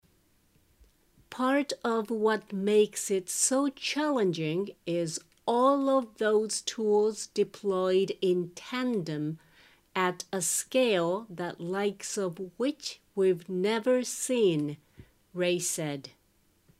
ゆっくり：